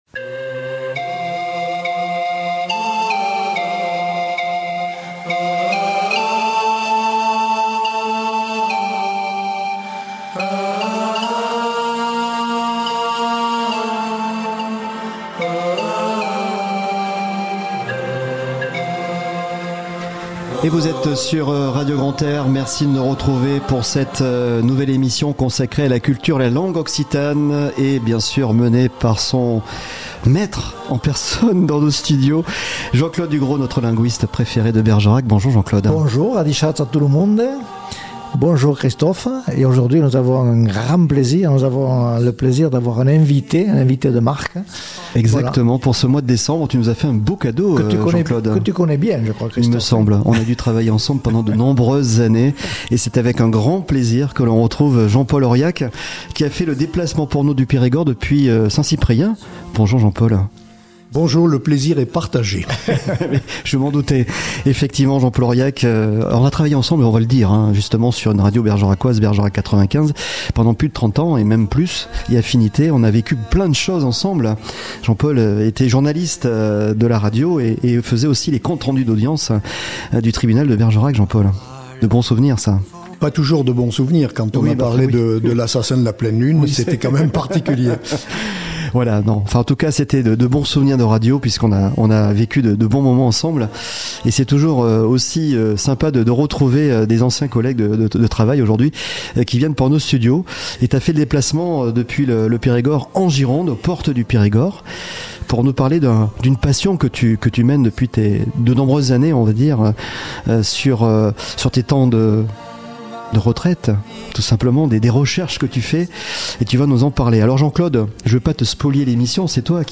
Le podcast du mois de Décembre 2022 #9 Parlèm Nostra Lenga, une émission consacrée à la culture et la langue occitane sur Radio Grand